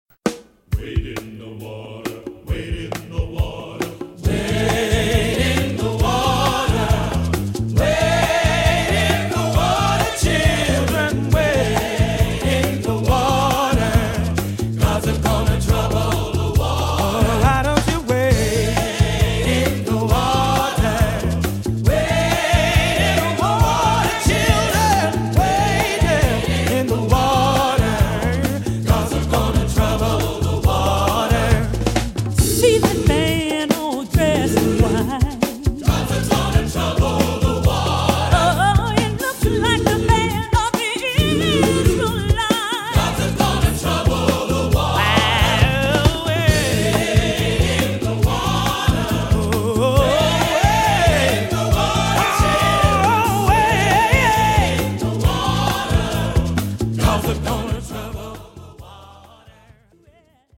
Music for Ailey School Horton